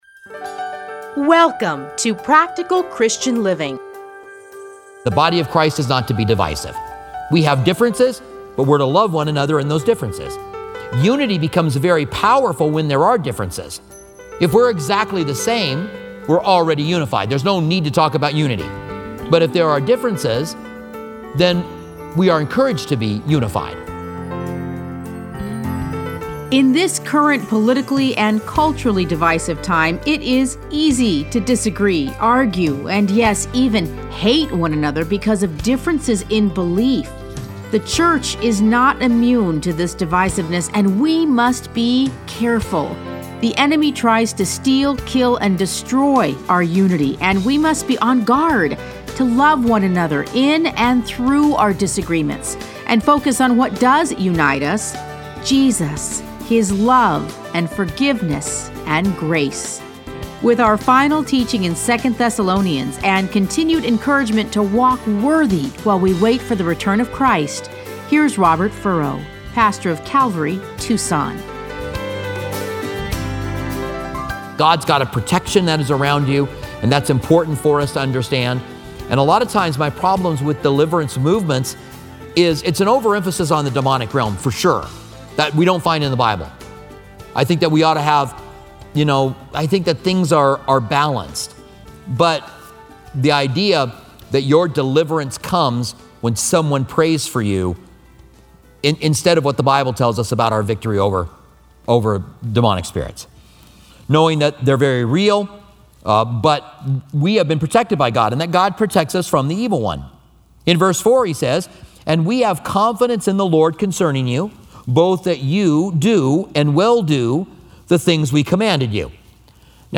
Listen to a teaching from 2 Thessalonians 3:1-18.